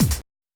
kick-hat.wav